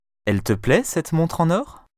les sons [ o ] bureau, tôt [ ɔ ] bonne, prof